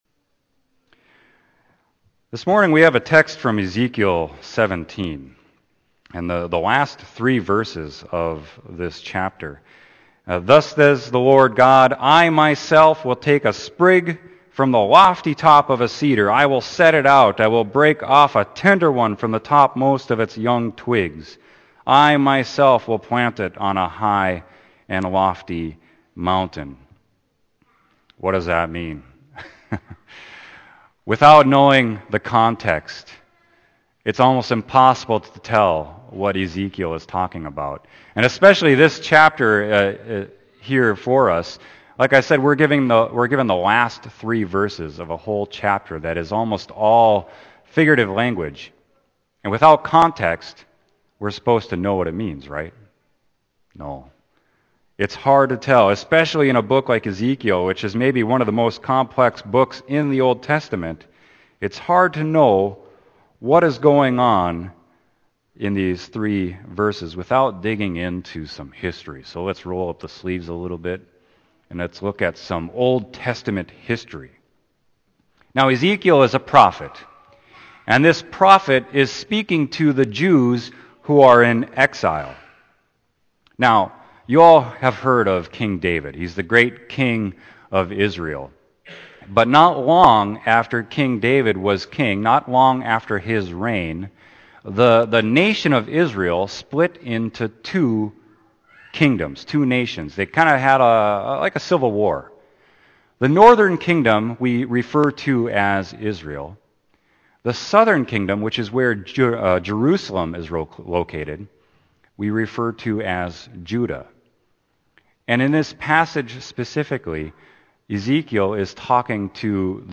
Sermon: Ezekiel 17.22-24